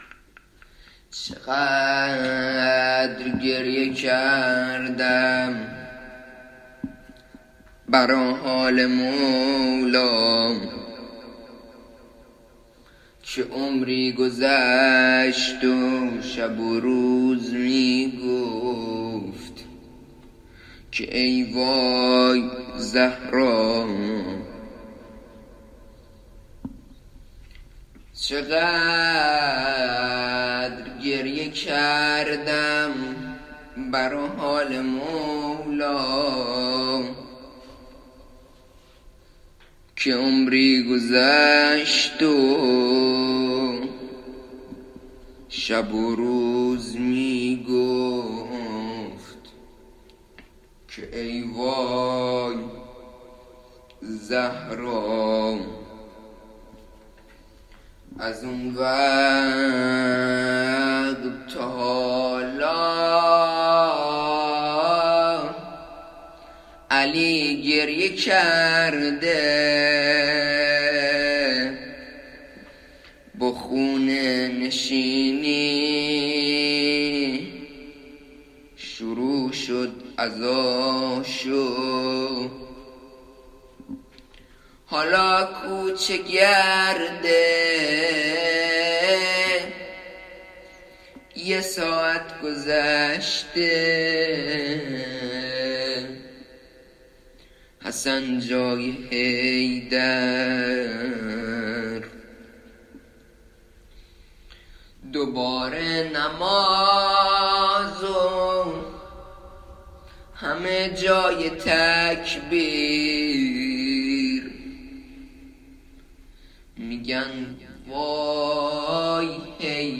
بدلیل شیوع ویروس کرونا این صوت درخانه ضبط شده است